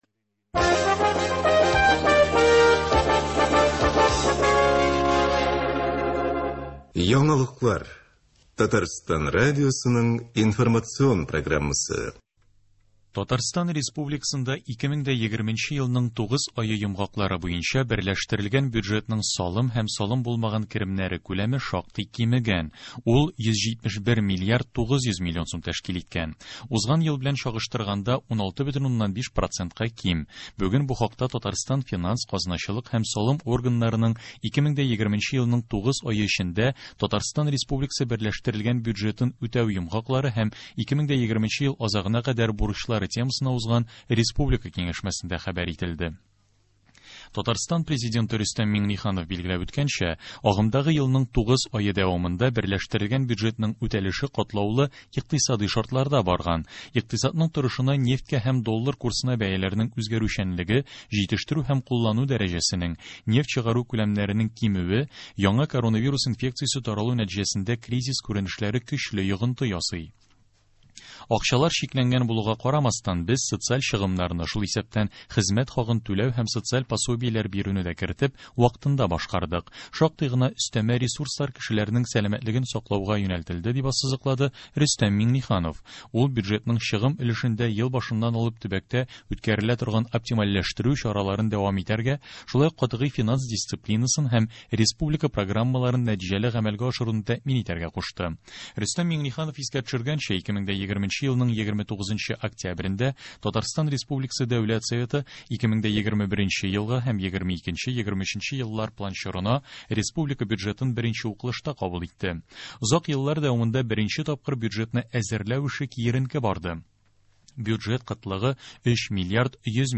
Яңалыклар (02.11.20)